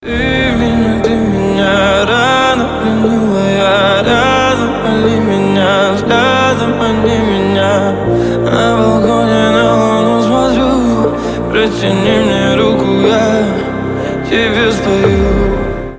• Качество: 128, Stereo
мужской вокал
рэп
лирика
спокойные
басы